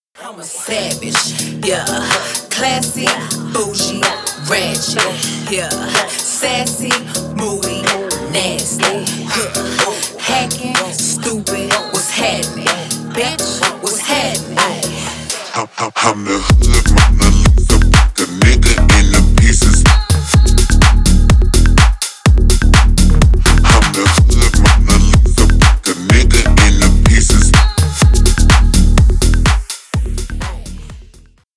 Поп Музыка # Рэп и Хип Хоп
клубные